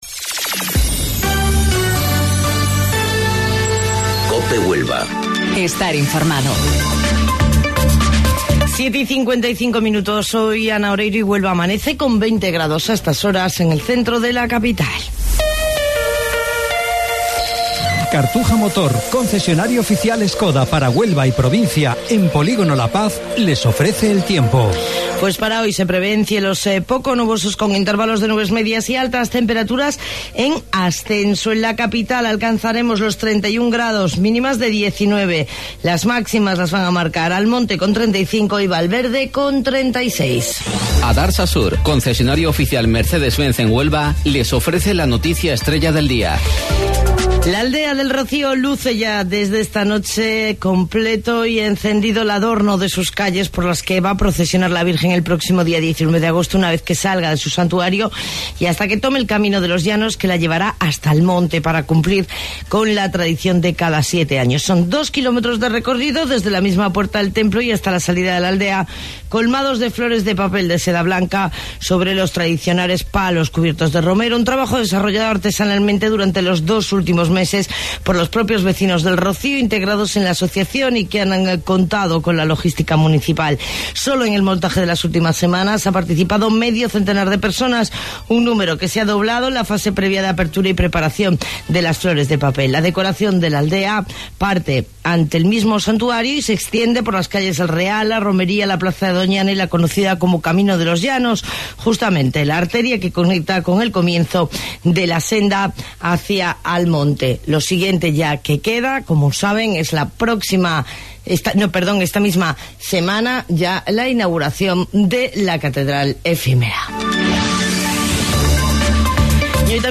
AUDIO: Informativo Local 07:55 del 13 de Agosto